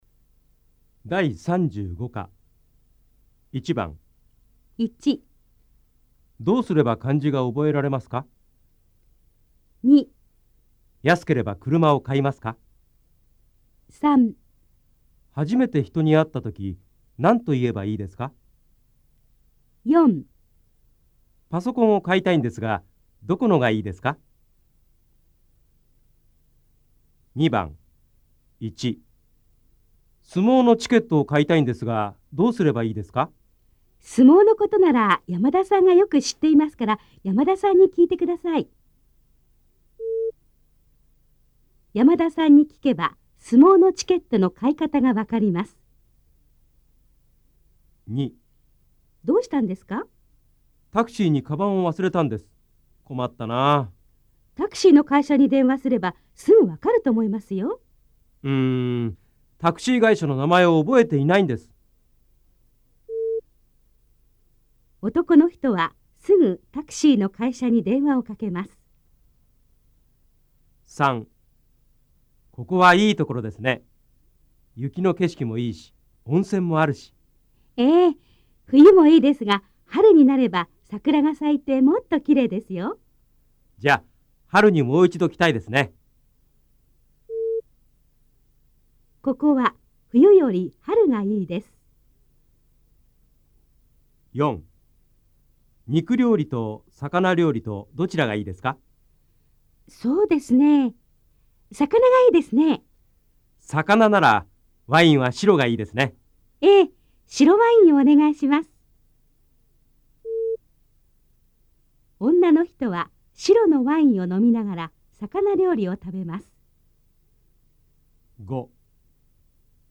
大家的日语-第35课听力练习